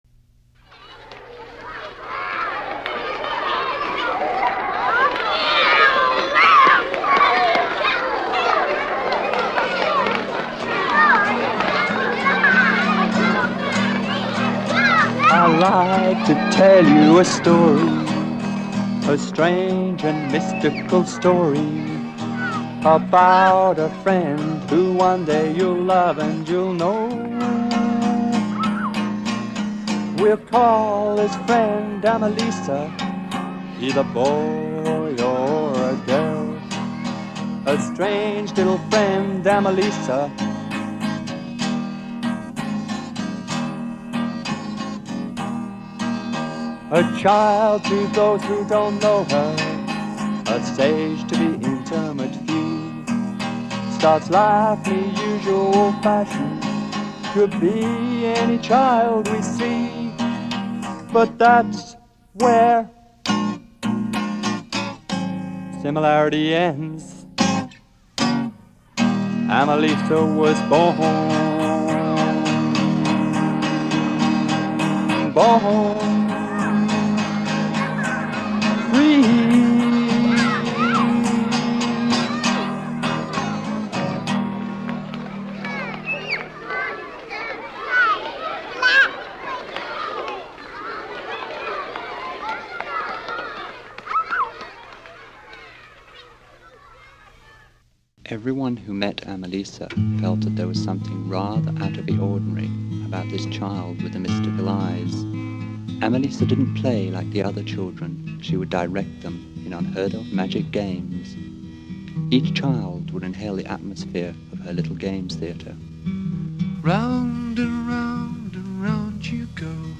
Mp3 of the start of the original 1975 demo tape of Amalisa made for a radio show in 12tET (10.7 meg) Go to individual song pages